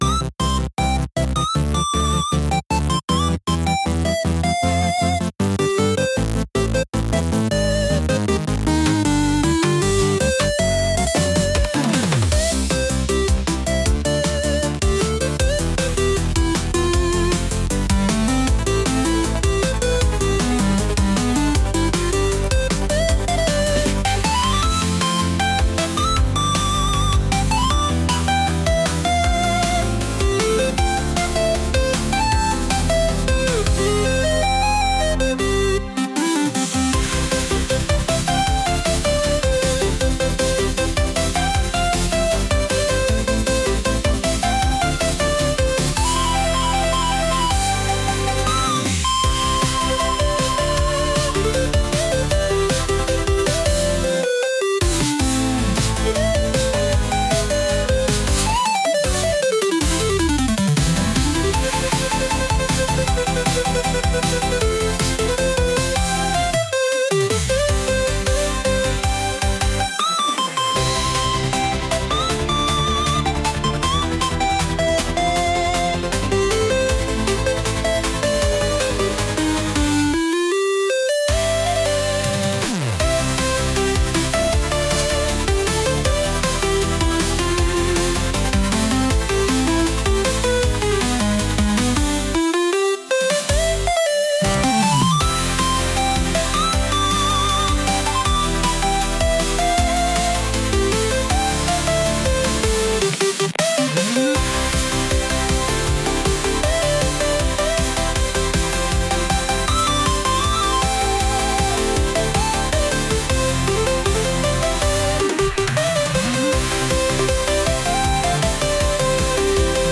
こたつに入って寝て起きるピコピコサウンド曲です。